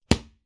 Close Combat Attack Sound 8.wav